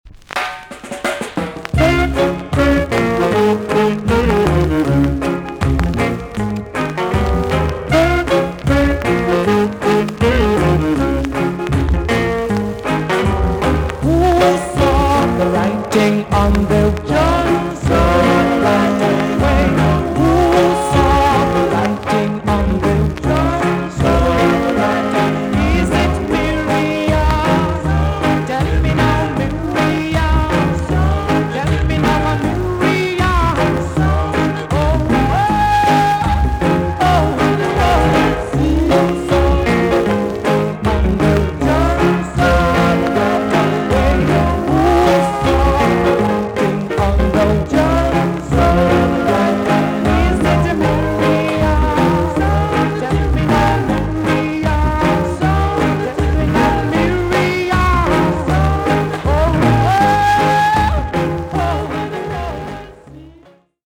VG ok 全体的にチリノイズが入ります。